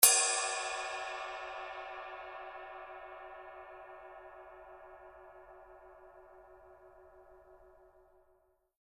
Caractère du son: Brillant, dur, énergique. Spectre assez étroit, mix légèrement complexe. Sensations lourdes. Ping vitreux, puissant qui devient plus sec, plus brut et boisé dans la zone non tournée et plus expressif, croustillant et riche dans la zone tournée. Halo assez brut et terreux qui est très serré et contrôlé. Cloche lourde et perçante. Cymbale ride tranchante et variée avec des zones de jeux distinctes pour une utilisation articulée dans les sets lourds.
signature_20_duo_ride_inner_body.mp3